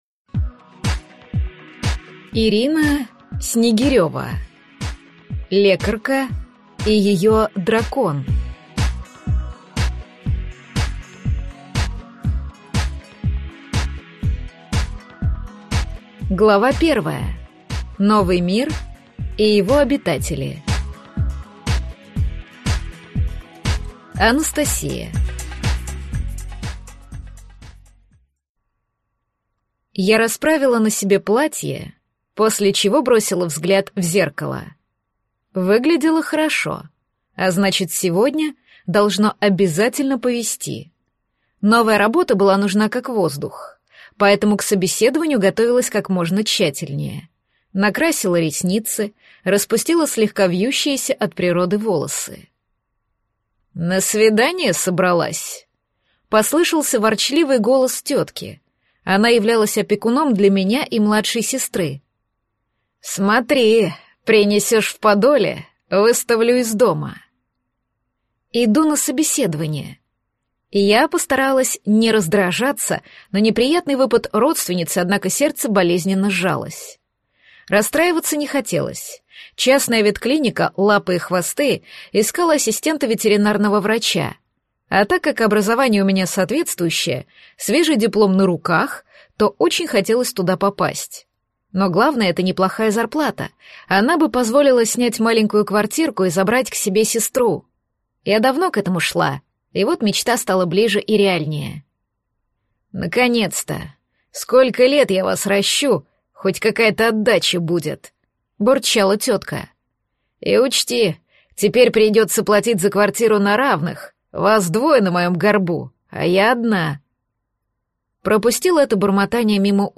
Аудиокнига Лекарка и её дракон | Библиотека аудиокниг